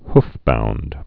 (hfbound, hf-)